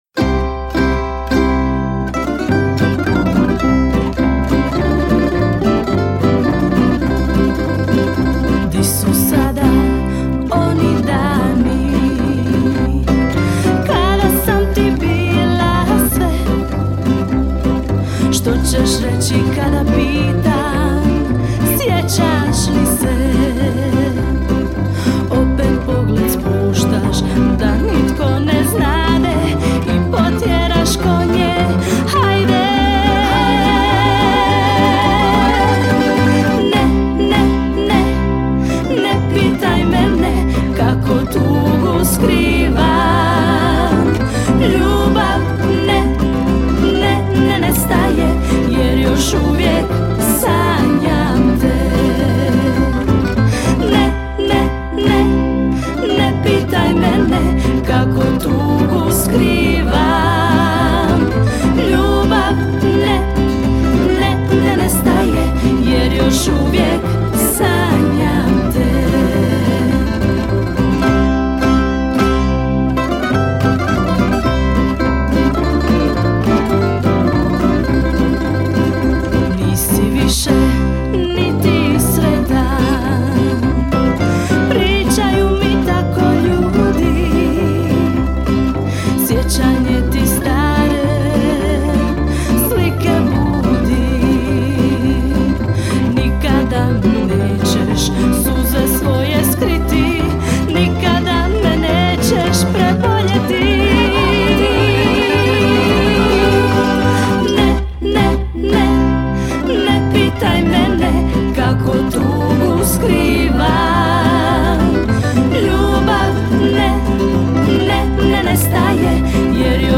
Festival pjevača amatera
Zvuci tamburice odzvanjali su prepunom dvoranom vatrogasnog doma u Kaptolu do kasnih noćnih sati.